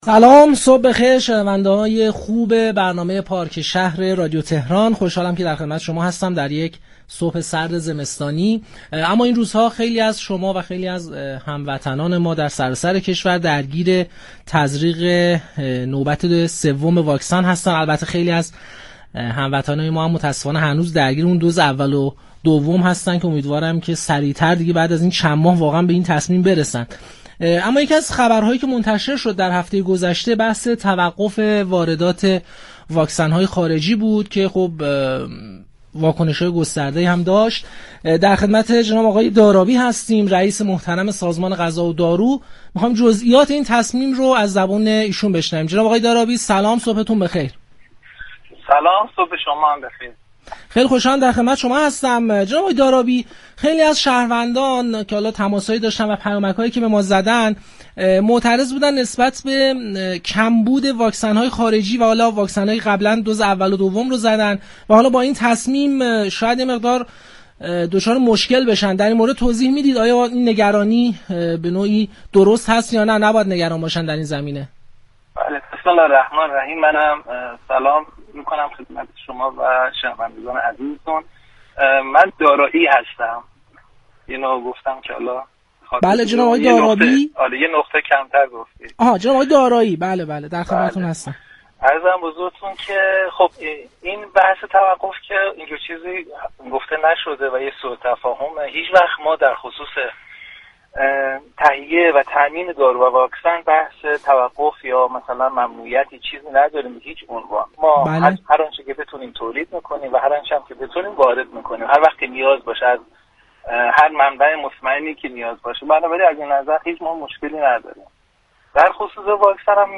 به گزارش پایگاه اطلاع رسانی رادیو تهران، بهرام دارایی رئیس سازمان غذا و دارو وزارت بهداشت در گفتگو با پارك شهر رادیو تهران درباره ممنوعیت واردات واكسن گفت: برای واردات دارو و واكسن هیچگونه ممنوعیتی نداریم هرآنچه بتوانیم واكسن داخلی تولید می‌كنیم و در صورت نیاز نیز واردات هم خواهیم داشت.